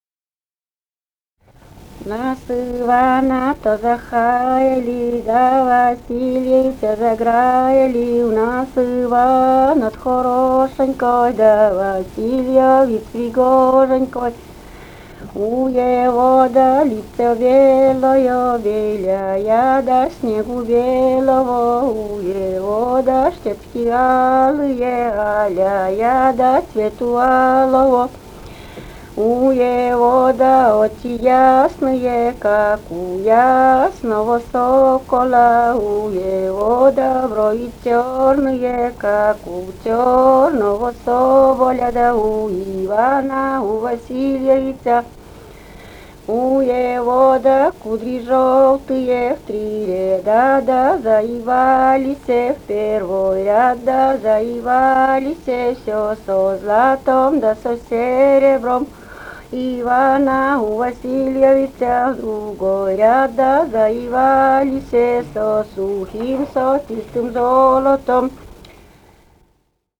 «В нас Ивана-то захаяли» (свадебная).